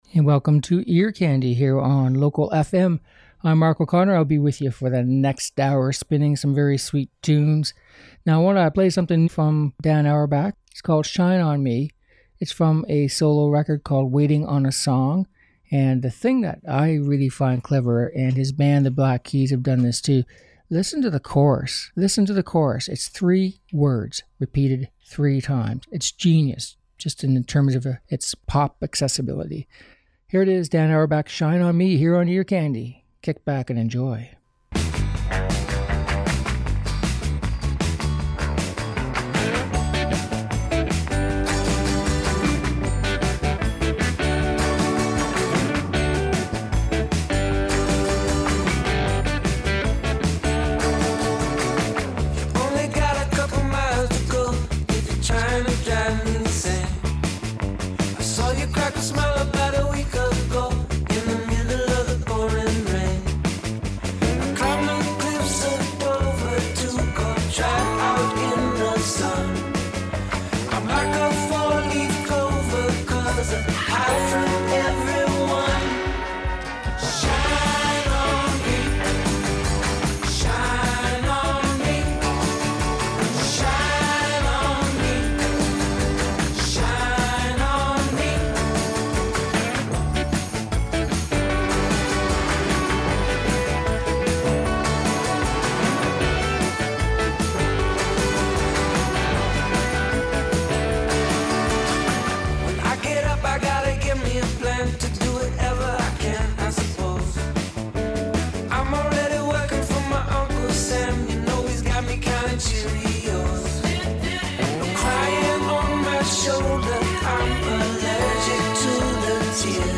Upbeat Pop and Rock Songs